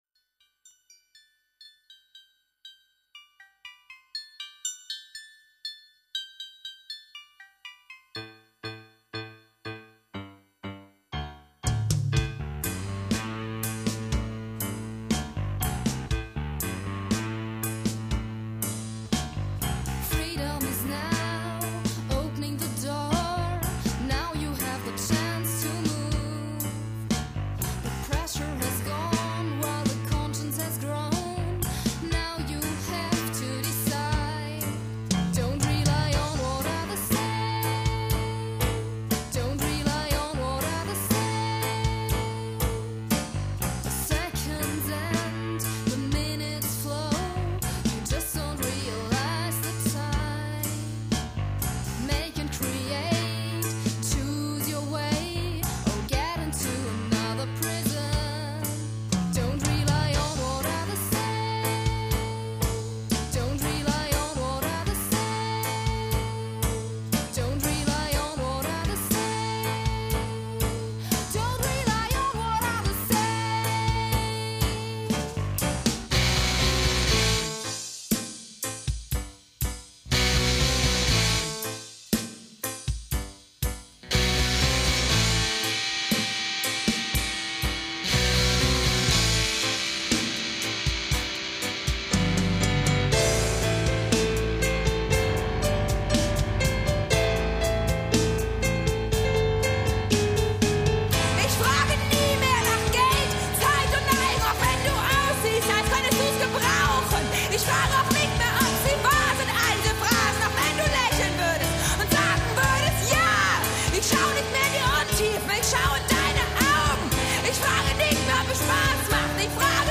wütenden Rock-Stomper